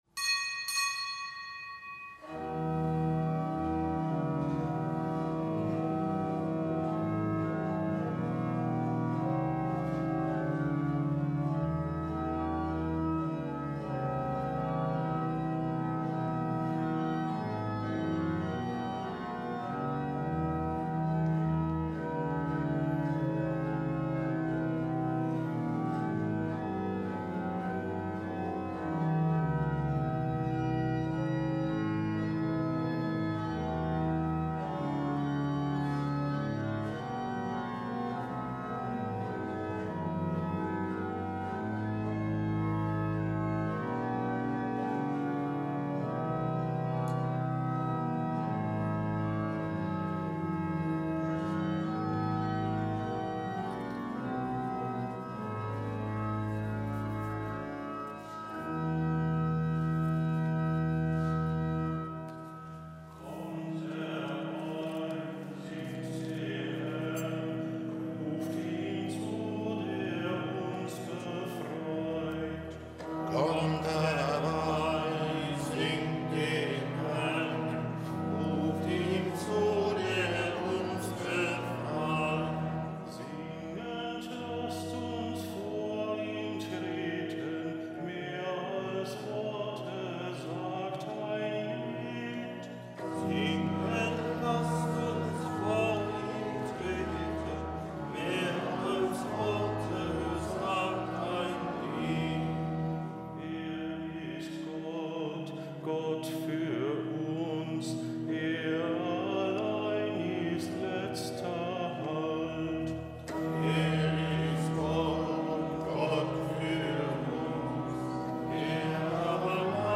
Kapitelsmesse aus dem Kölner Dom am Mittwoch der fünften Woche im Jahreskreis. Zelebrant: Weihbischof Dominikus Schwaderlapp